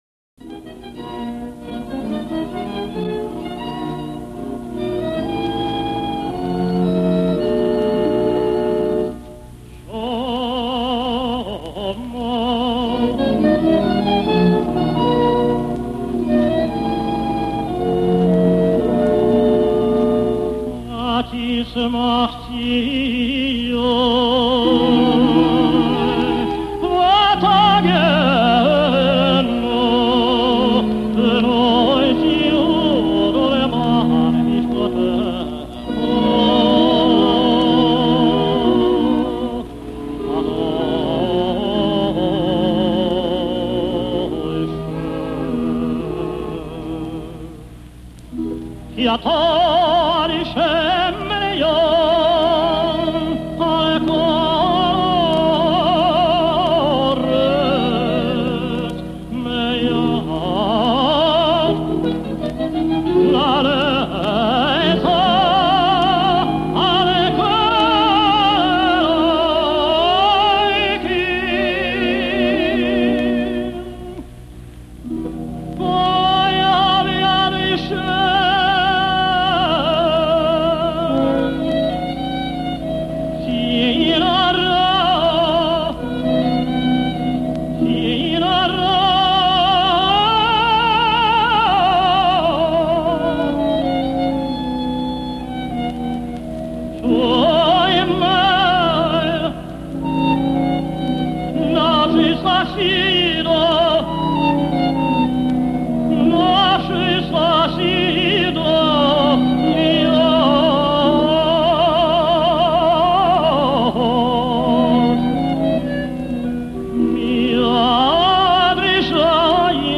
לחובבי חזנות
גיליתי הקלטה מקונצרט ''מוזיקה יהודית אומנותית'' יצירות קלאסית שמבוססות על ניגוני כליזמר...